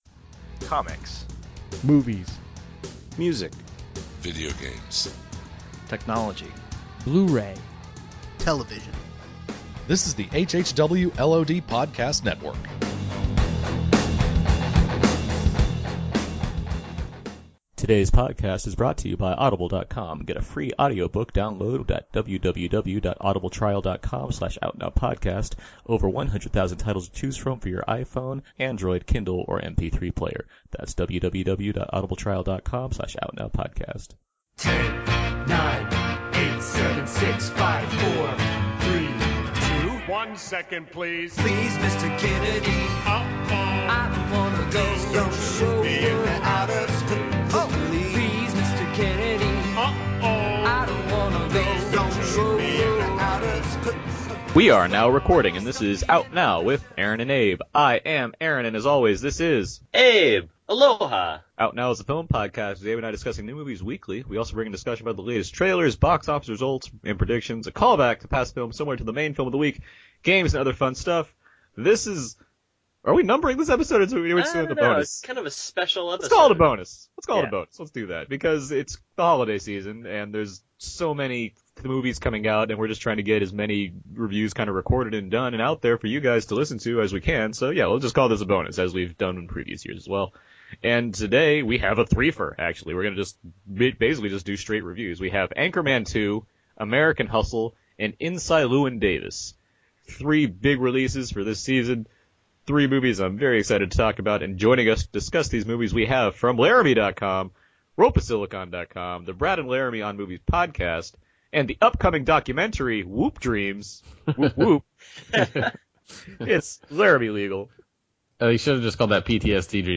There is also a little bit of time for games, some impressions, and of course plenty of singing.